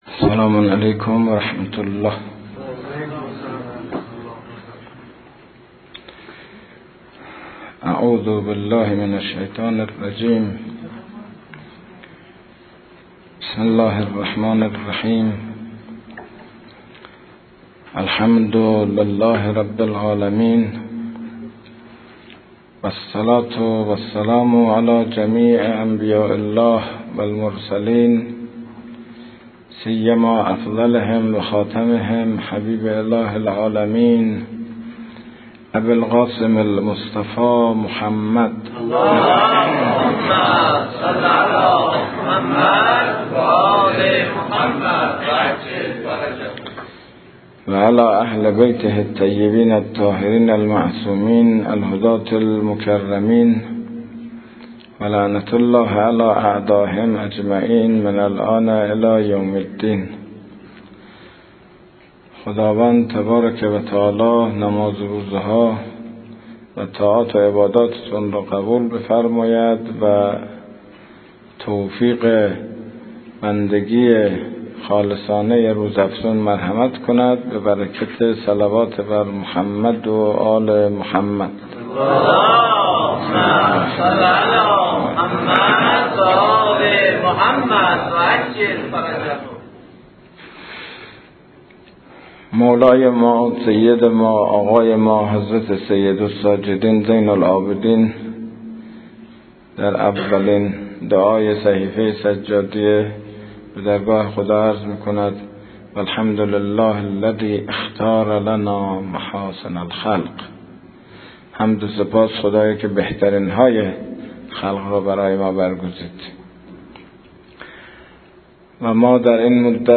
این مباحث در ماه مبارک رمضان سال 1397 بیان شده است.